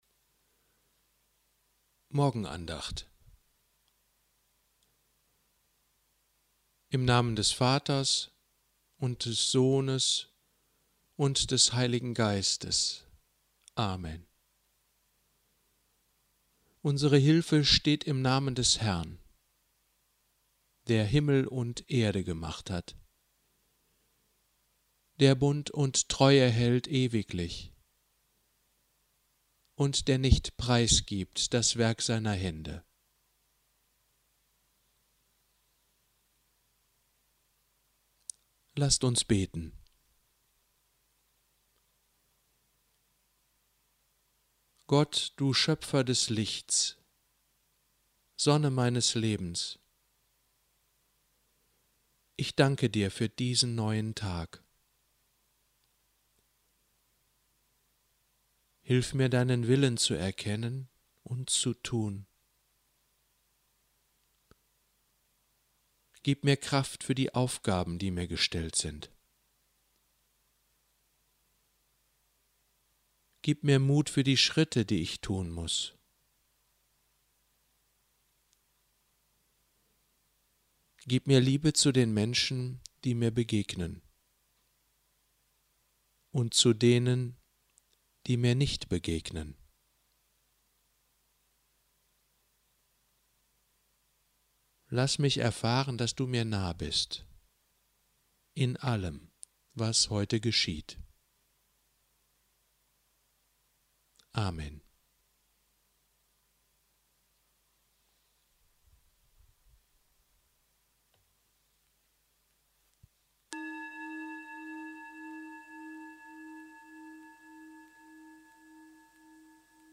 Morgenandacht